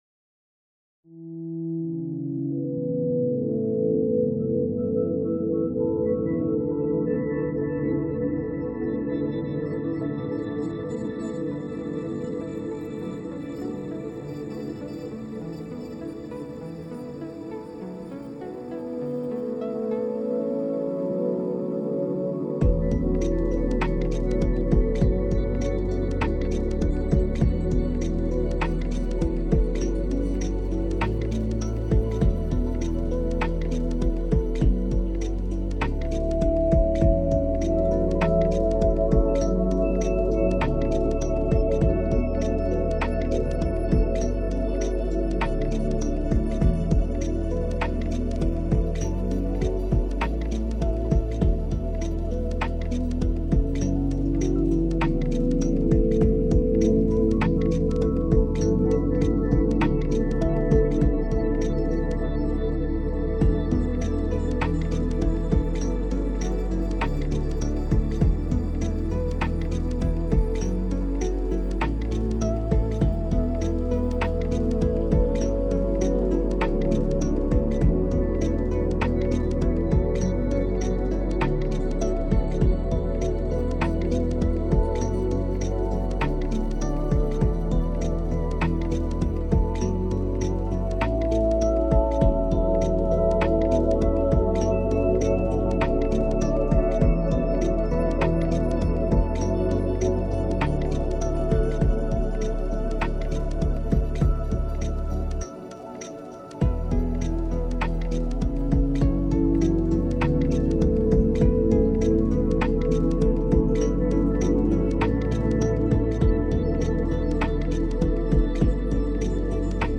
Ambient tune with lo-fi, chill, sparkling mixed vibes